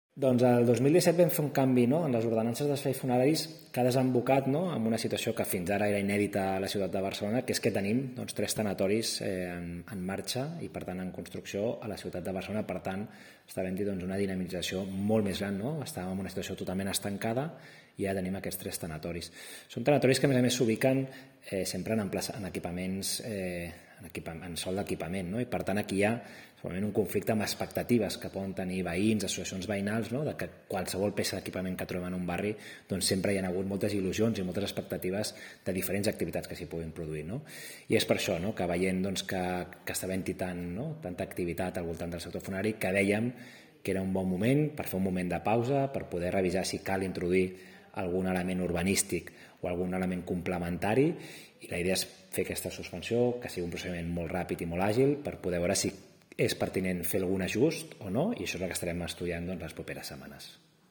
Declaracions d’Eloi Badia (MP3)